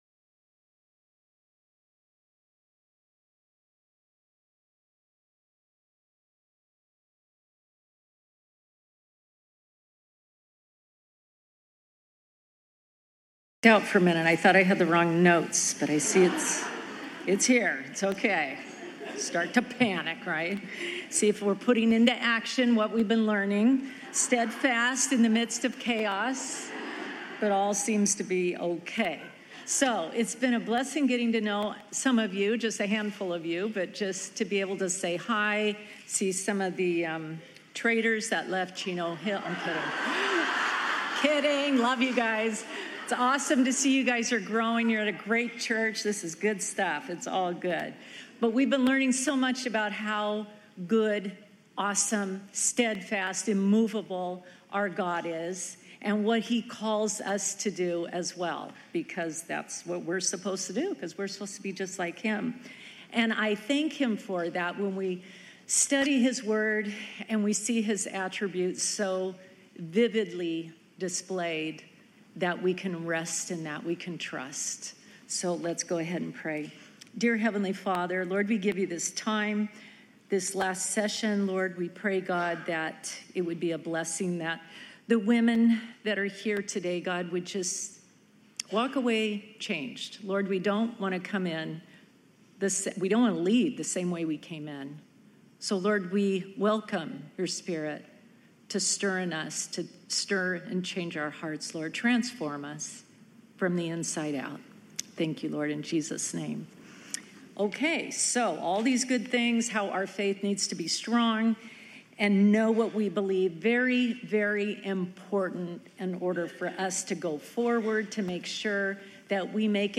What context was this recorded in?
Welcome to Calvary Chapel Knoxville!